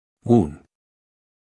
Phoneme_(Umshk)_(Uun)_(Male).mp3